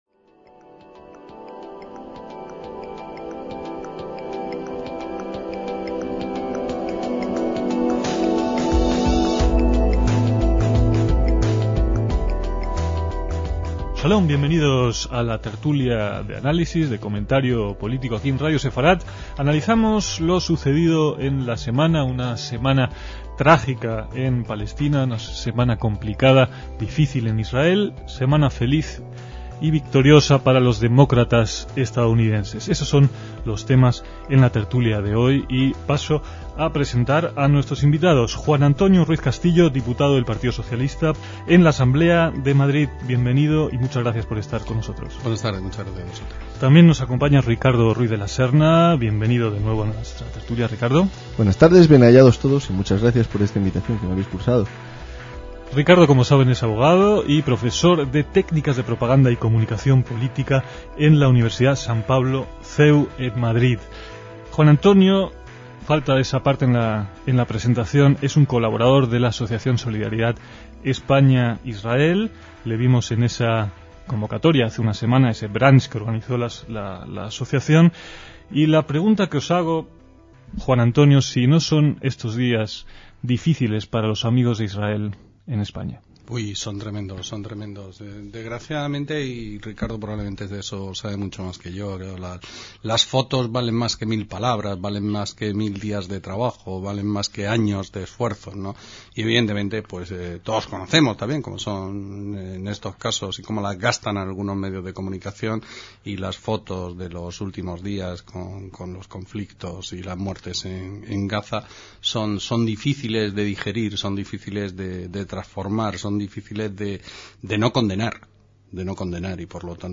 DECÍAMOS AYER (11/11/2006) - El tema principal de esta tertulia fue un ataque fallido de Israel contra la franja de Gaza que provocó la muerte de dieciocho civiles palestinos en la localidad de Beit Hanun, además de comentar la victoria de los demócratas estadounidenses en las elecciones para el Senado con Hillary Clinton al frente.